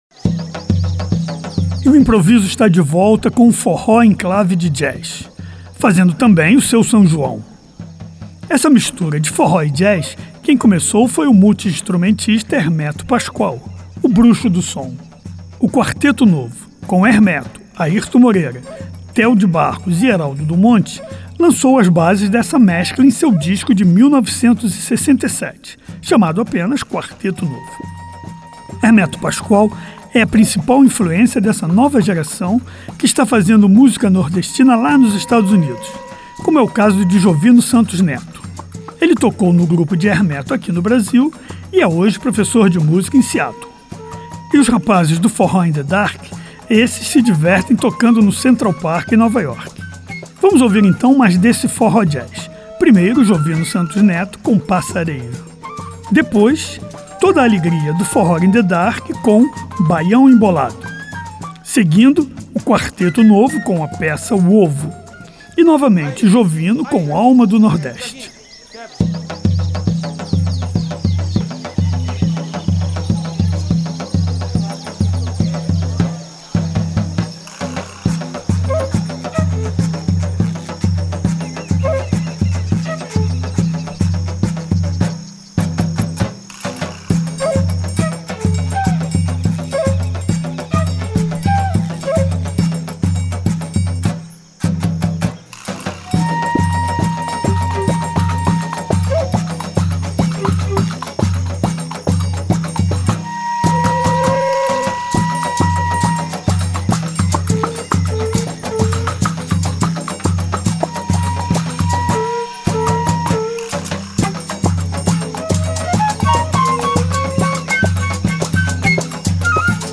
multi-instrumentista